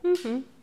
hum-acquiescement_01.wav